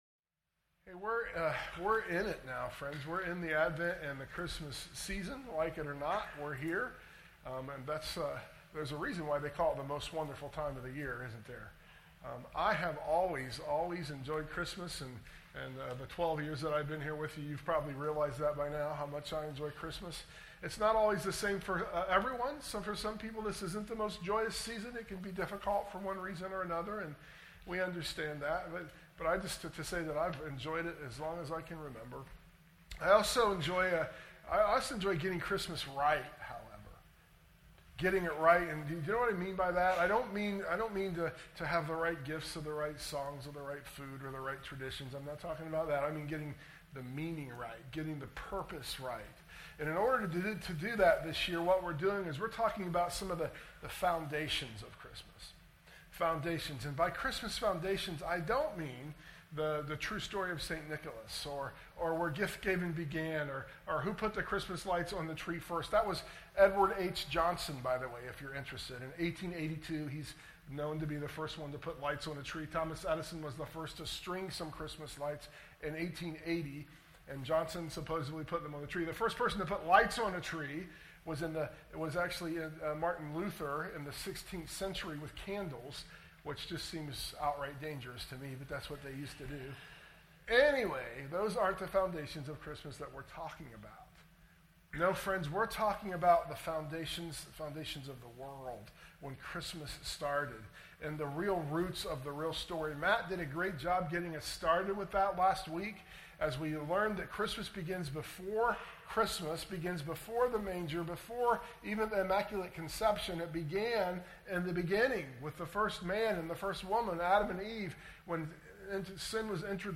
sermon_audio_mixdown_12_8_24.mp3